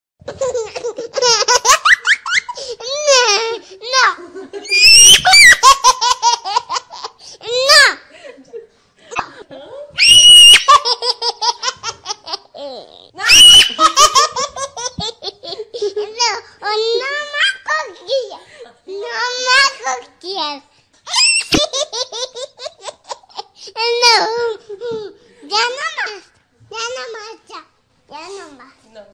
Catégorie Drôle